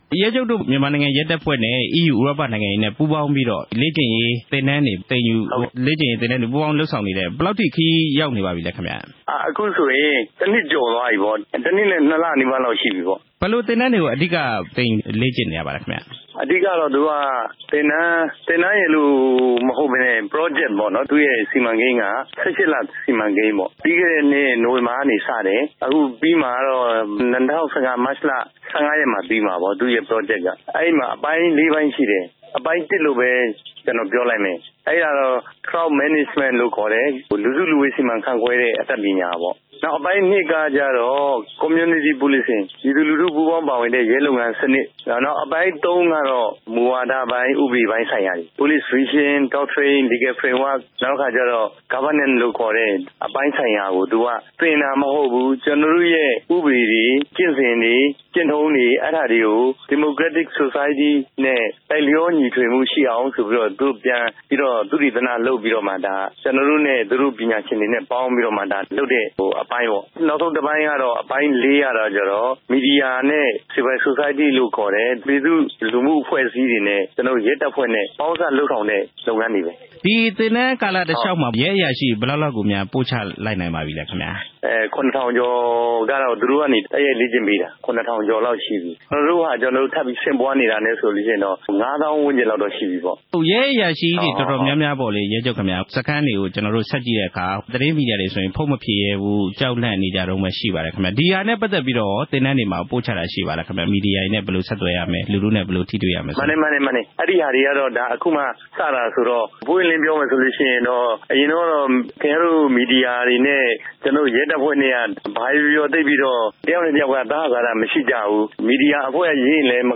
မြန်မာနိုင်ငံရဲတပ်ဖွဲ့ လေ့ကျင့်ရေး အရာရှိချုပ် ရဲမှူးချုပ် သူရဘိုနီနဲ့ မေးမြန်းချက်